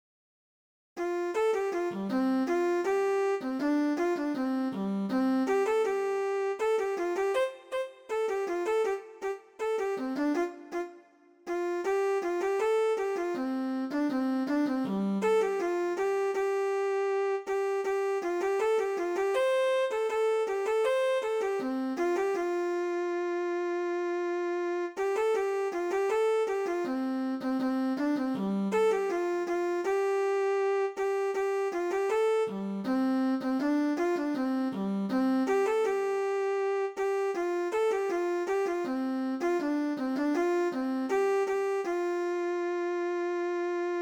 THIẾU NHI CA